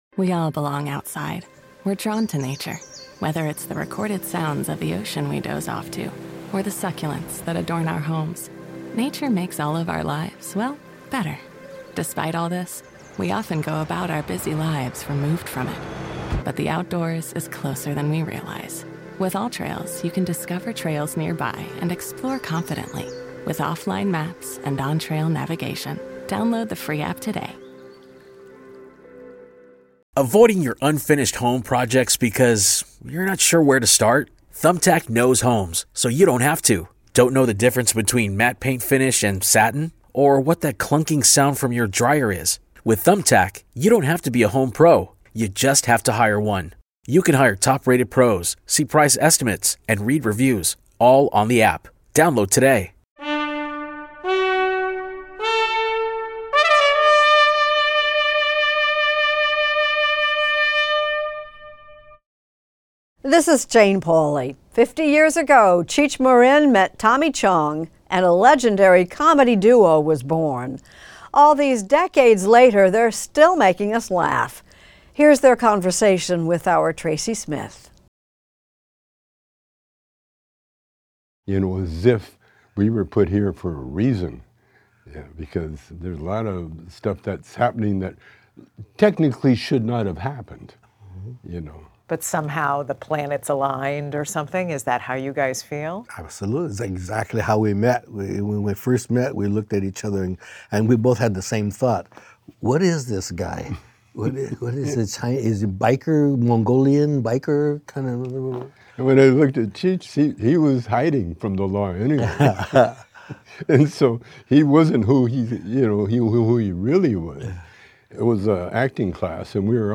Extended Interview: Cheech and Chong the Laid-back Potheads